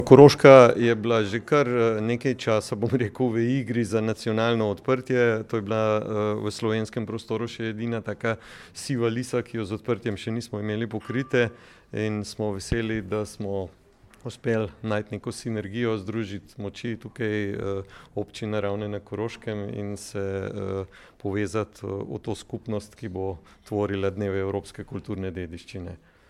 Zakaj so letos izbrali Ravne na Koroškem, je povedal Jernej Hudolin, generalni direktor Zavoda za varstvo kulturne dediščine Slovenije:
izjava Hudolin.mp3